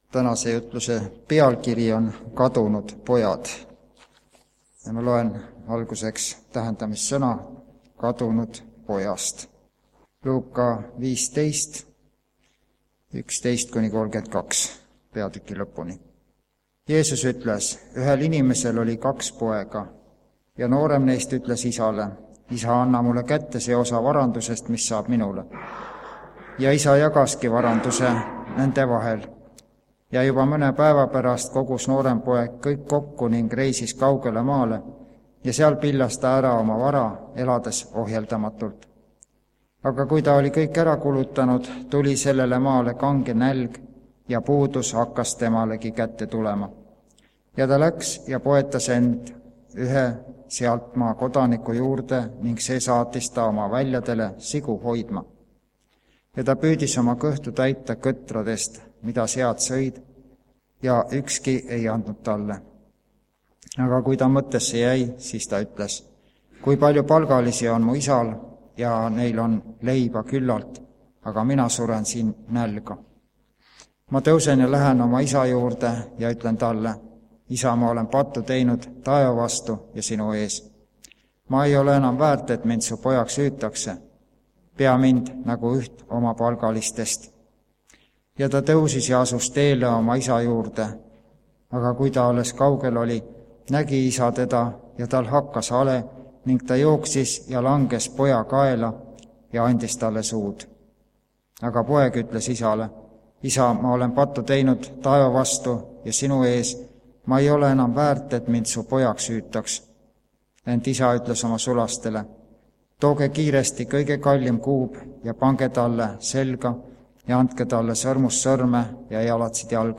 KADUNUD POJAD Muusikahetk on aga üle 50 aasta vana. Meeskvartett laulab laulu "Kadunud poeg"